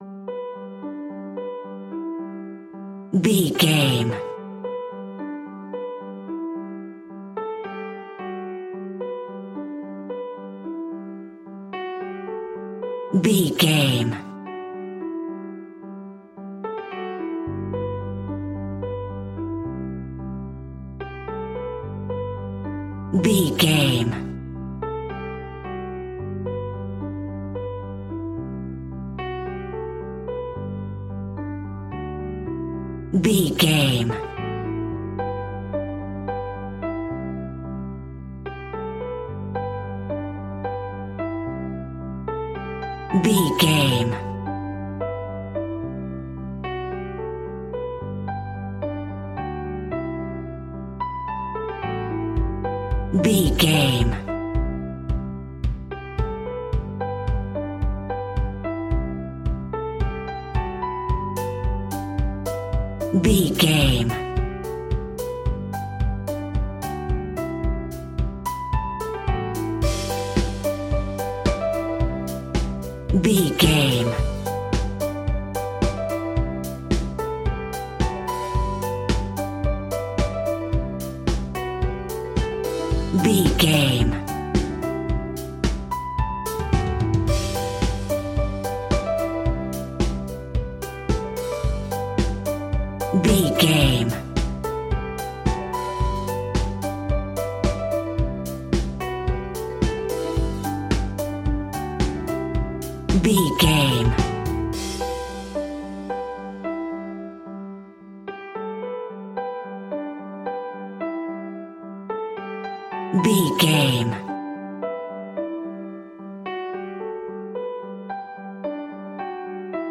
Motivation Business Pop Rock Music Alt.
Ionian/Major
pop rock
indie pop
energetic
uplifting
upbeat
groovy
guitars
bass
drums
piano
organ